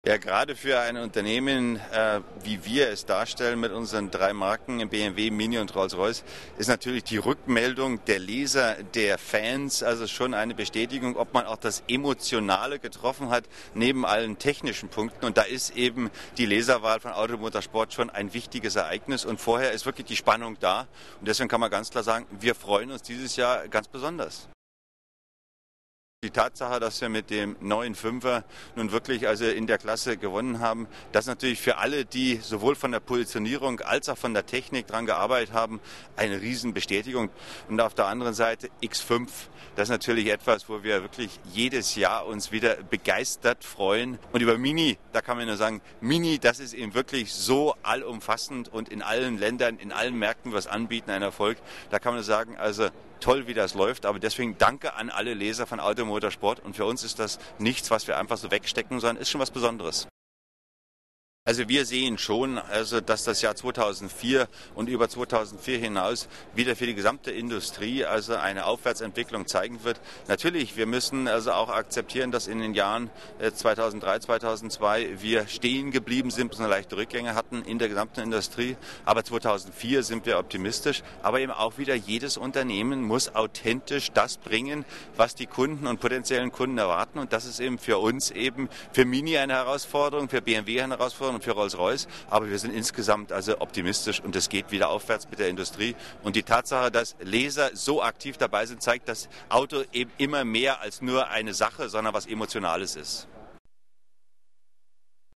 Fragen an BMW-Chef Helmut Panke zu „Die besten Autos 2004“ (O-Ton im MP3-Format, 1:45 Min., 824 kB)